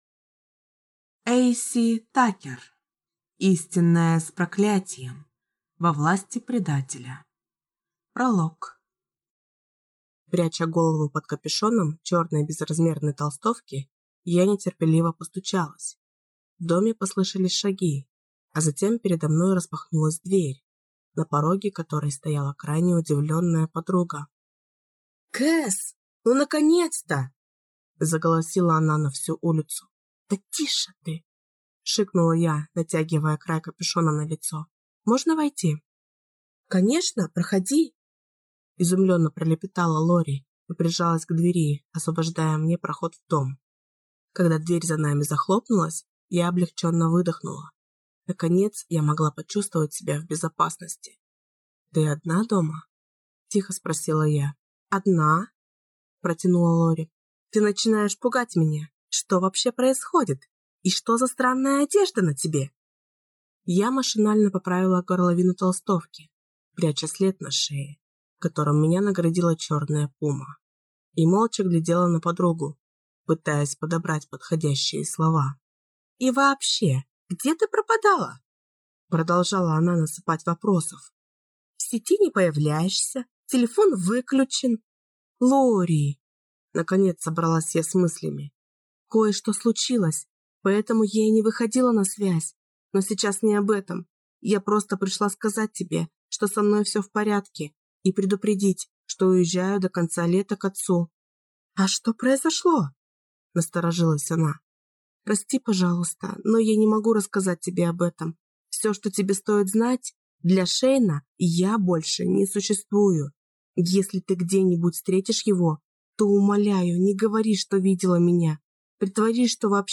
Аудиокнига Истинная с проклятием. Во власти предателя | Библиотека аудиокниг